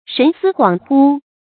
神思恍惚 shén sī huǎng hū 成语解释 恍惚：神志不清。
成语繁体 神思怳惚 成语简拼 sshh 成语注音 ㄕㄣˊ ㄙㄧ ㄏㄨㄤˇ ㄏㄨ 常用程度 常用成语 感情色彩 中性成语 成语用法 主谓式；作谓语；形容人神情不安定 成语结构 主谓式成语 产生年代 古代成语 成语正音 思，不能读作“shī”。